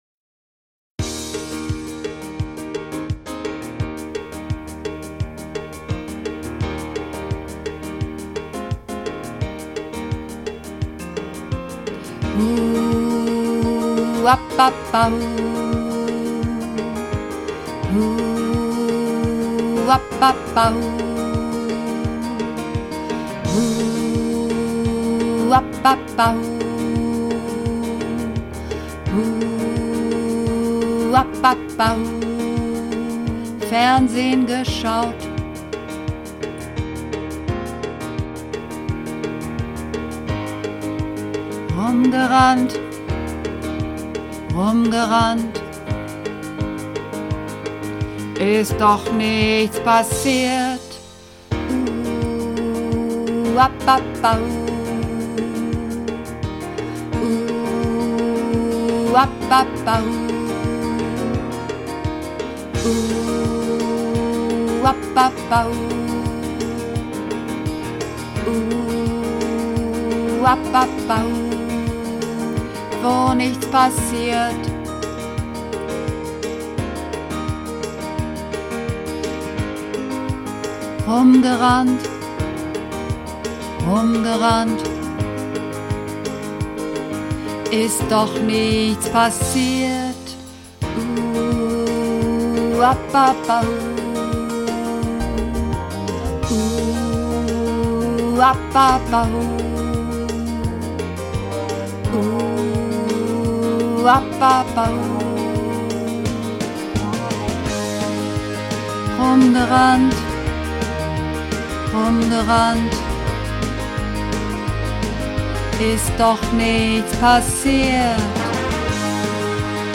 Übungsaufnahmen - Langeweile
Langeweile (Bass - Frauen)
Langeweile__2_Bass_Frauen.mp3